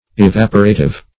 Evaporative \E*vap"o*ra*tive\, a. [L. evaporatius: cf. F.